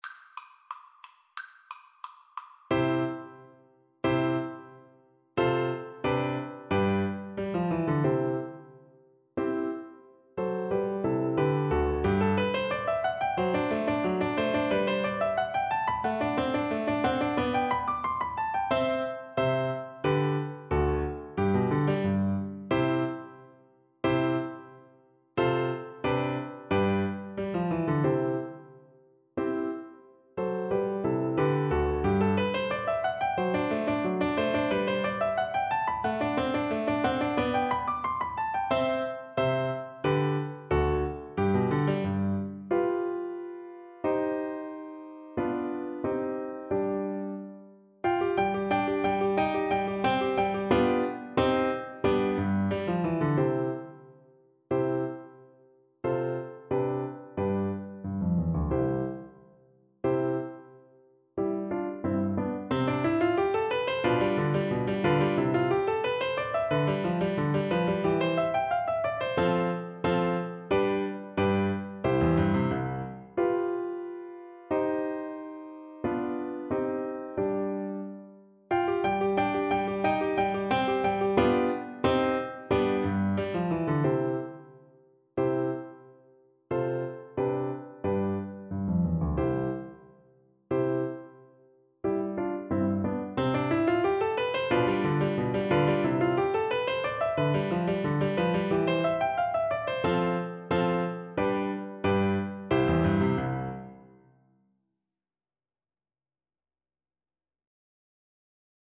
C major (Sounding Pitch) G major (French Horn in F) (View more C major Music for French Horn )
Spiritoso Spiritoso = 180
2/2 (View more 2/2 Music)
Classical (View more Classical French Horn Music)